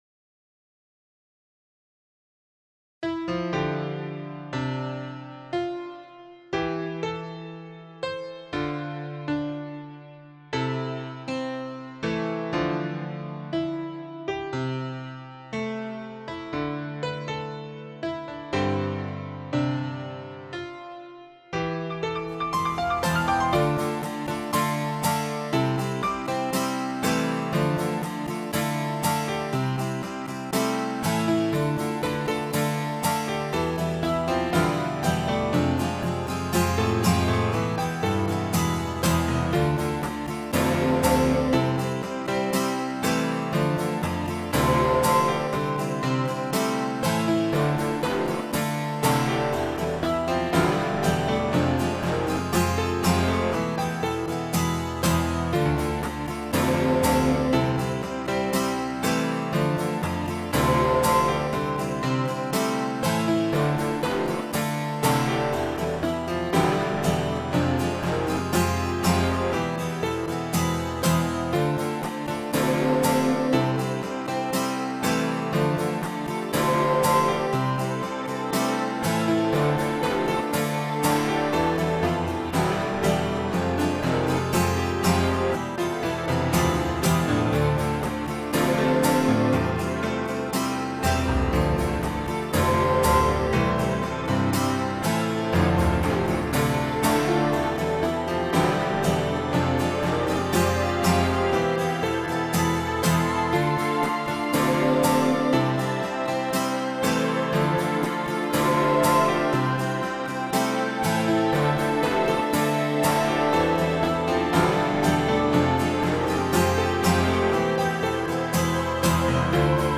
Aber leider ebenfalls ohne Gesang...